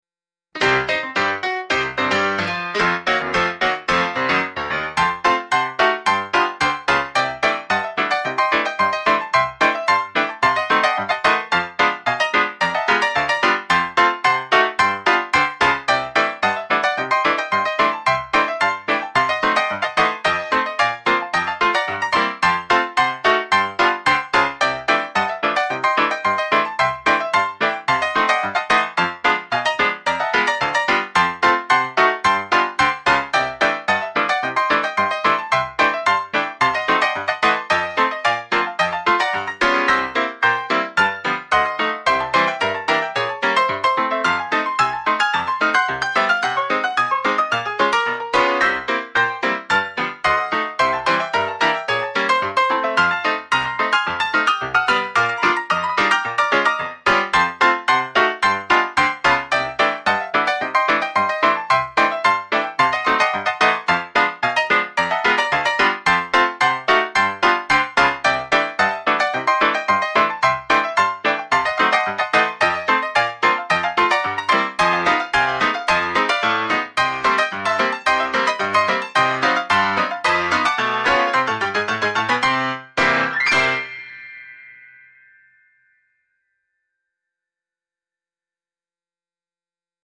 goofy ragtime piano with honky-tonk feel and slapstick energy